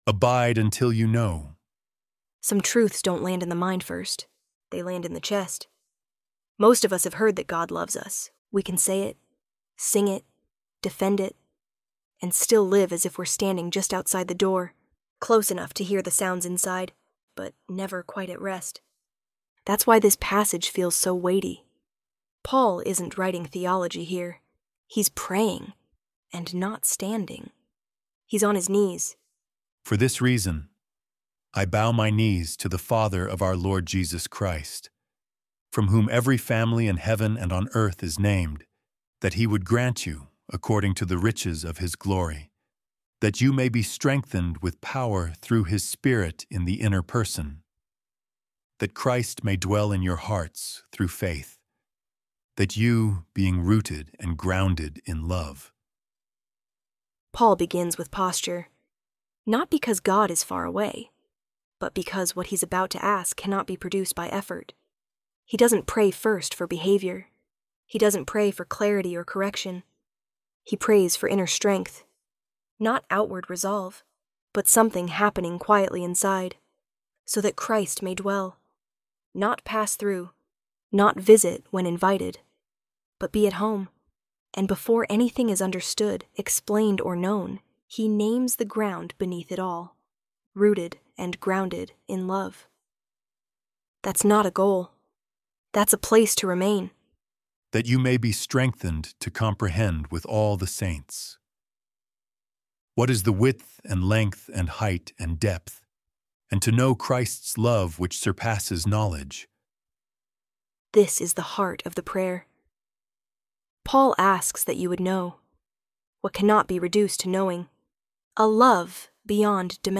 ElevenLabs_Abide_Until_You_Know_V2.mp3